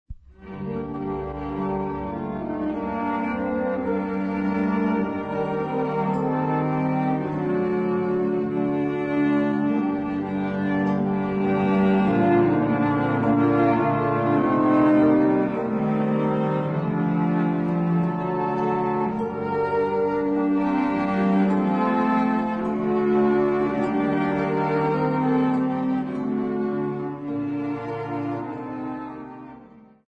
Classical music South Africa
Country dancing South Africa
field recordings
Country dances song with classical instruments accompaniment.